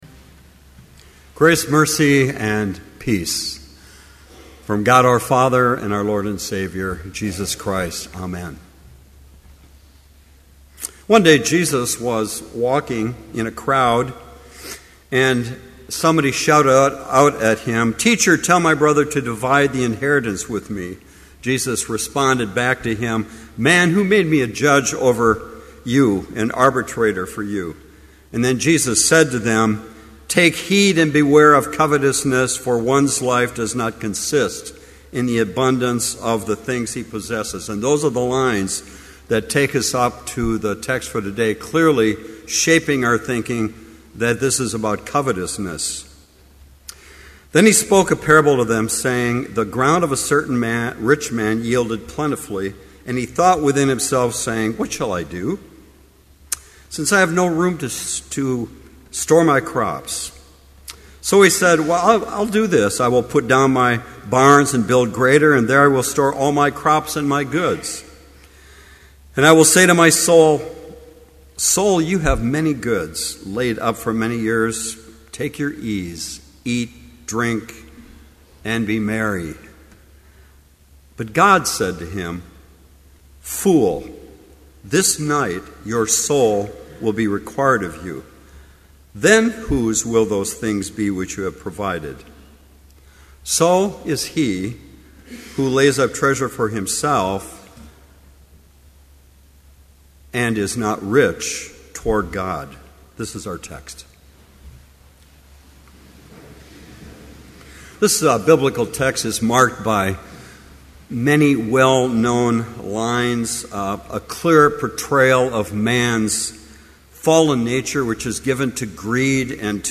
Complete service audio for Chapel - March 22, 2012